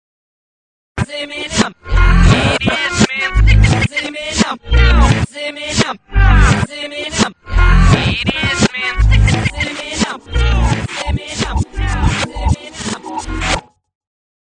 hip hop
Tipo di backmasking Bifronte